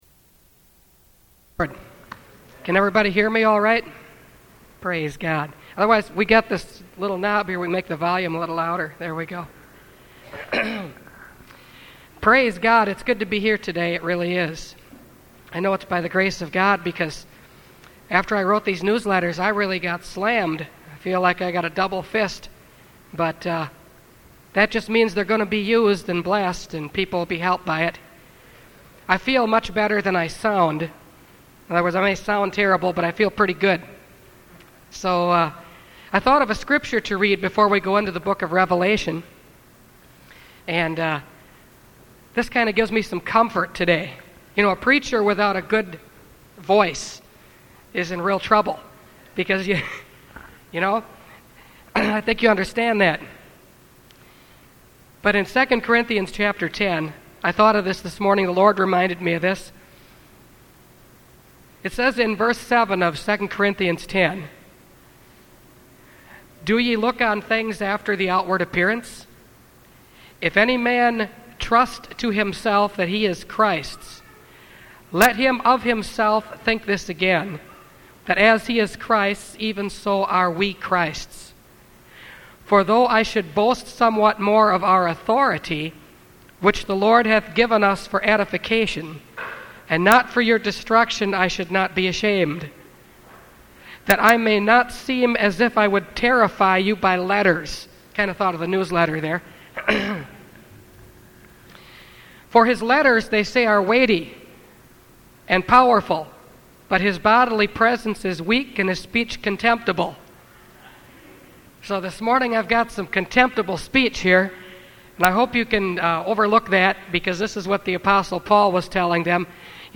Revelation Series – Part 7 – Last Trumpet Ministries – Truth Tabernacle – Sermon Library